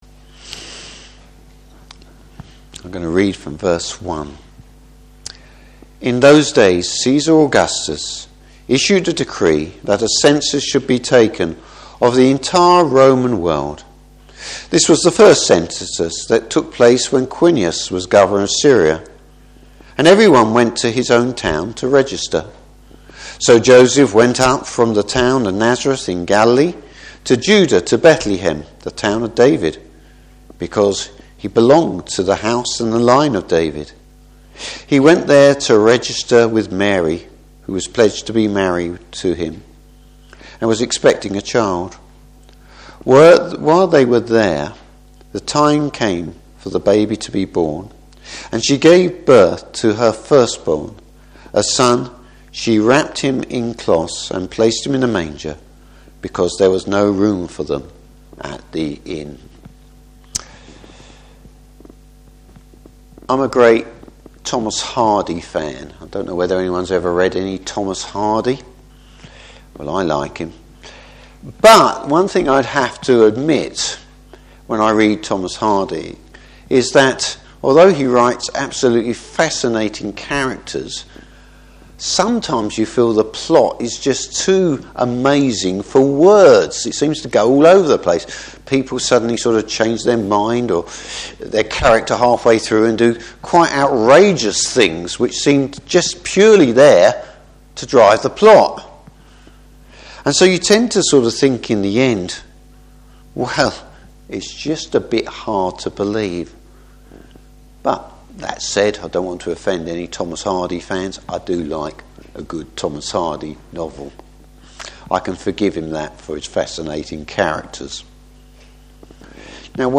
Service Type: Carol Service The reason Jesus came.